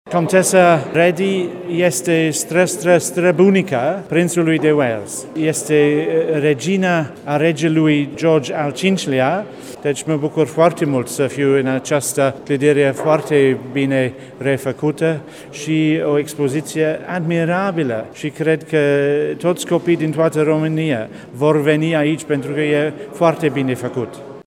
Ambasadorul Regatului Unit la București, Andrew Noble a transmis și un mesaj din partea Prințului Charles.